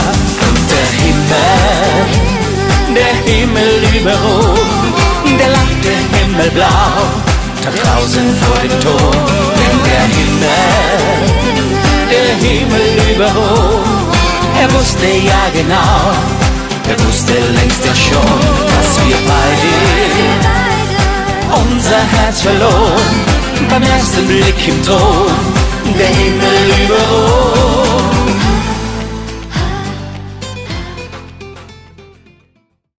Die Schlagerband mit Herz für jeden Anlass